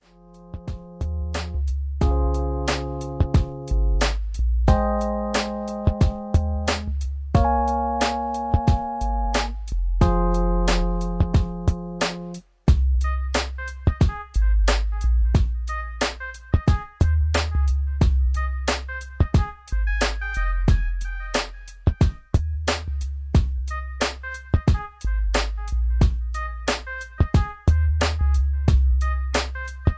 Nice R&B track with Dirty South vibe